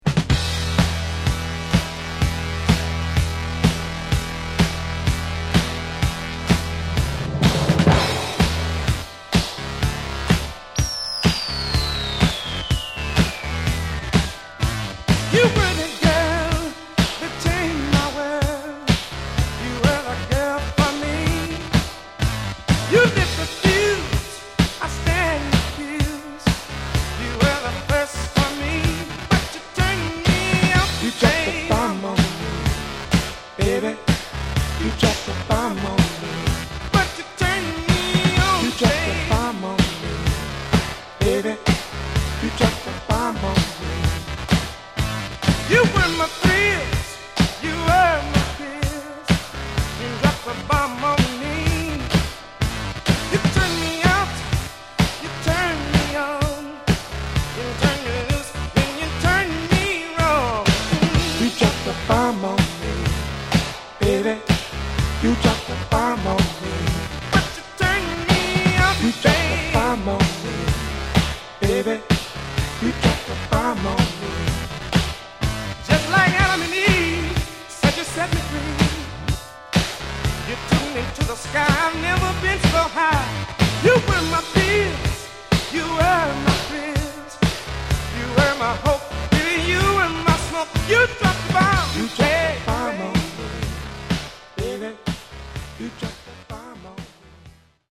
The Audio is pristine Mint.